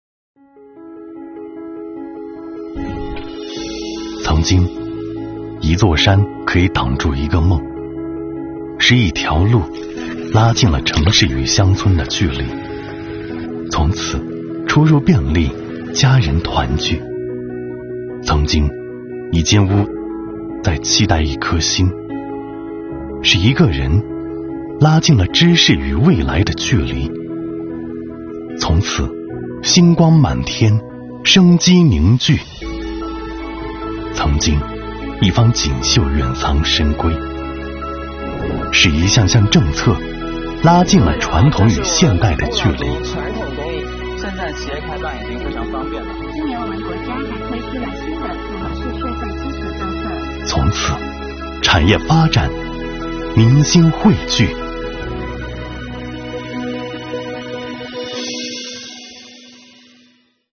公益广告 | 税情暖民，拉近距离